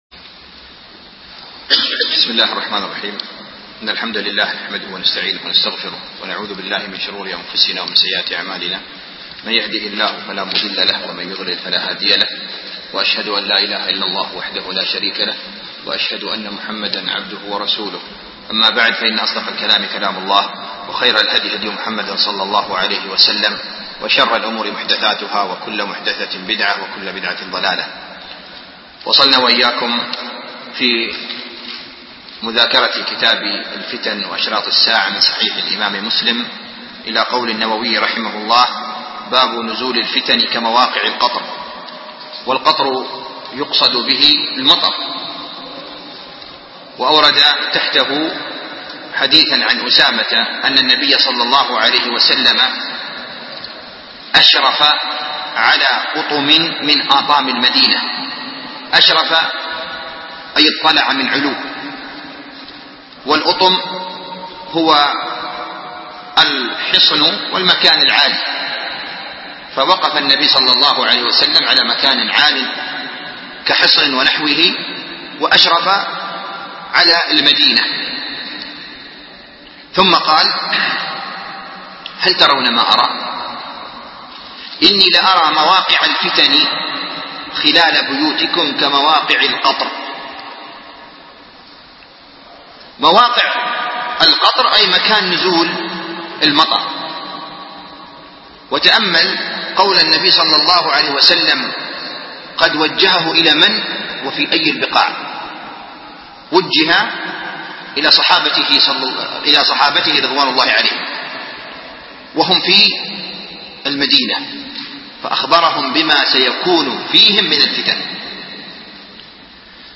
شرح كتاب الفتن من صحيح مسلم - الدرس الثالث